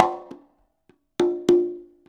100 CONGAS10.wav